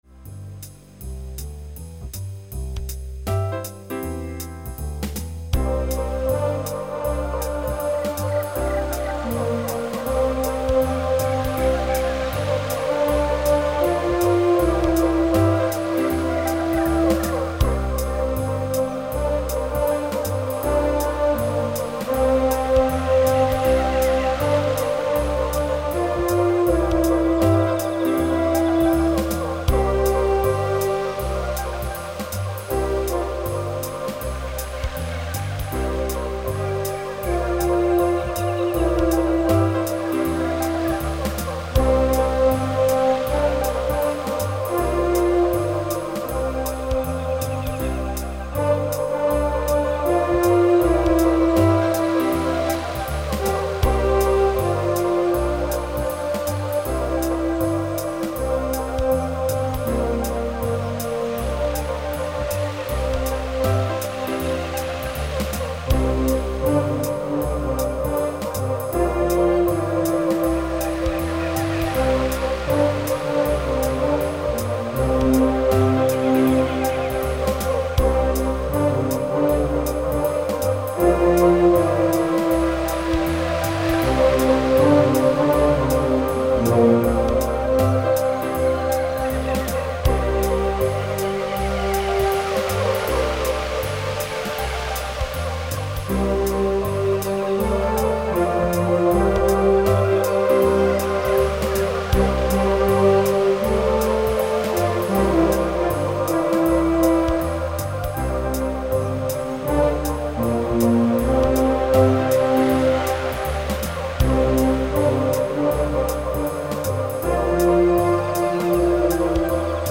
Style: Instrumental - Folk